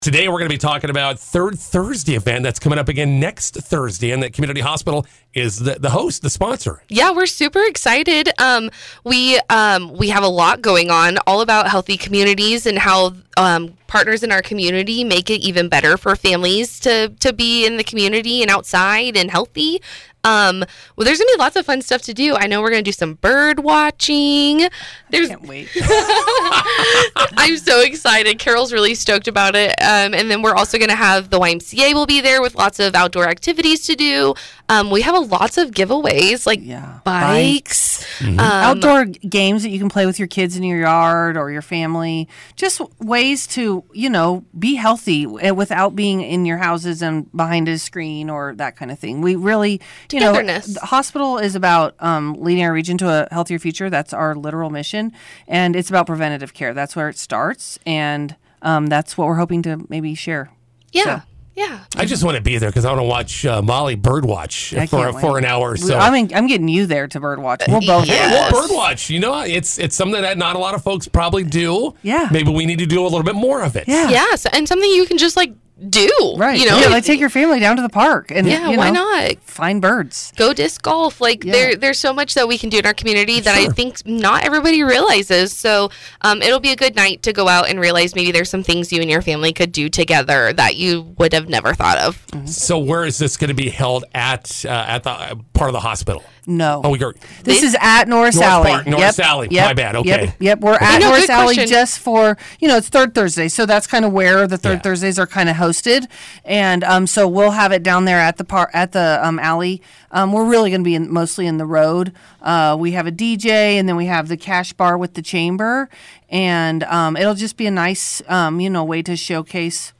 INTERVIEW: Community Hospital hosting “Third Thursday” event this week.